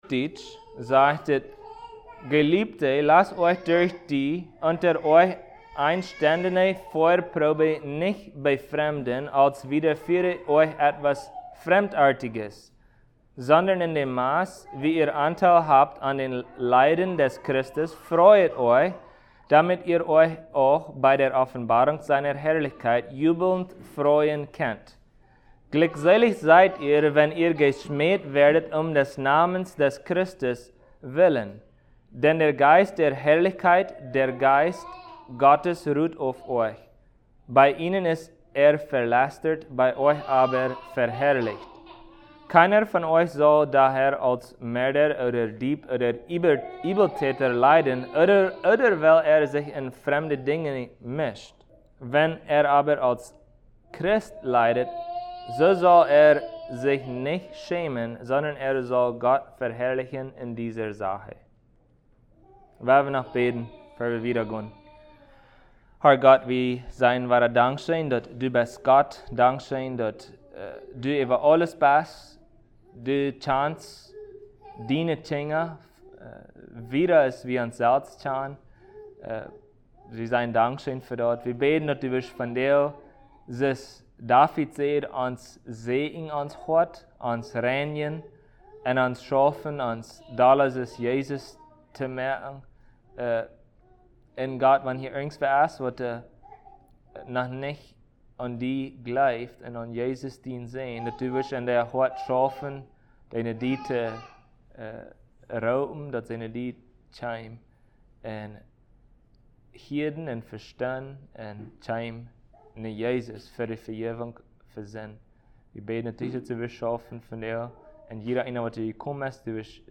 Passage: 1 Peter 4:12-16 Service Type: Sunday Plautdietsch